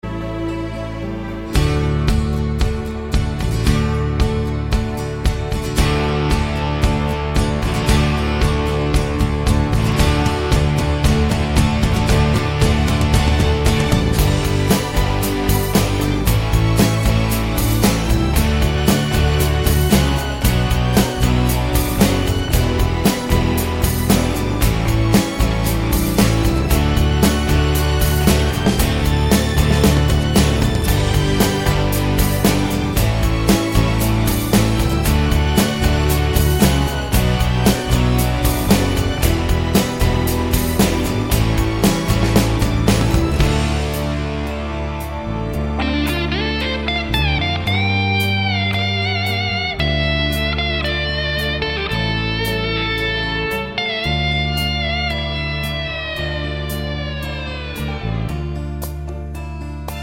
no Backing Vocals Irish 3:29 Buy £1.50